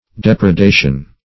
Depredation \Dep`re*da"tion\, n. [L. depraedatio: cf. F.